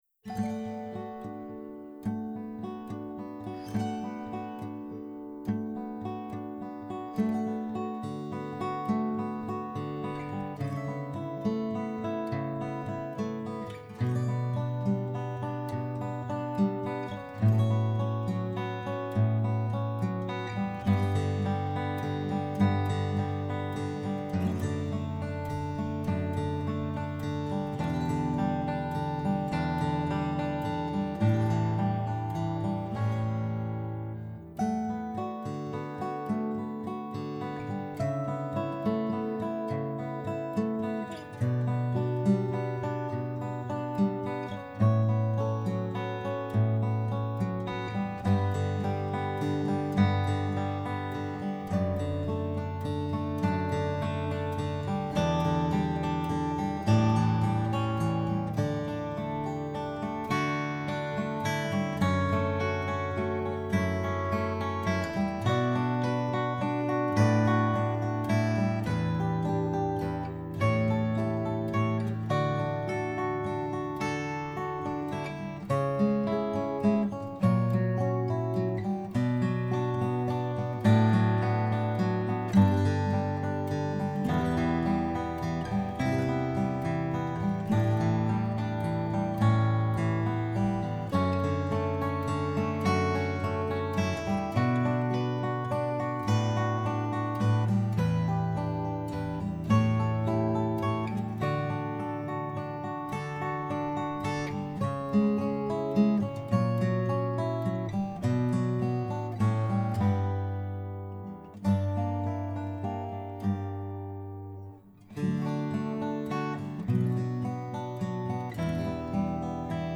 I decided my song would benefit from a slower tempo so the guitar finger picking could be appreciated.
When I finished editing the lower guitar tracks, I recorded another, higher guitar part.
Below, I share some other instrumental versions of my song that I will eventually share on Insight Timer.